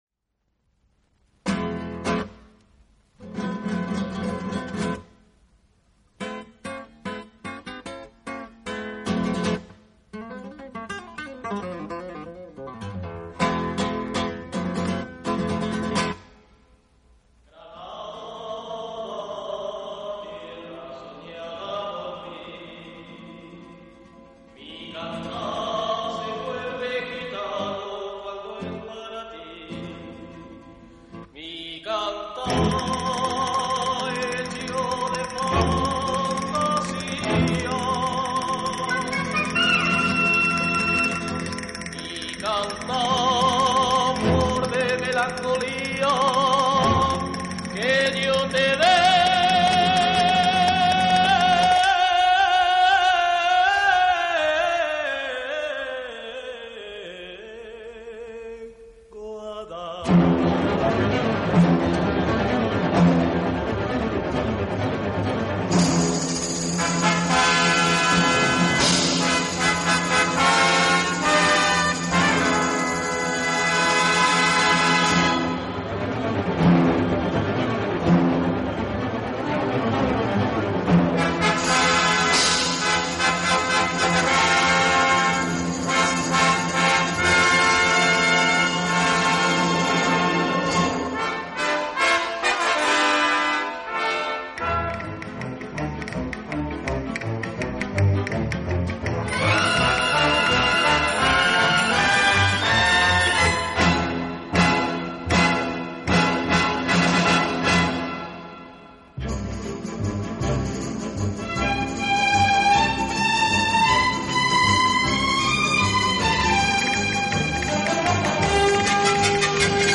【轻音乐专辑】
演奏以轻音乐和舞曲为主。
他自己演奏钢琴，改编乐曲，指挥乐队。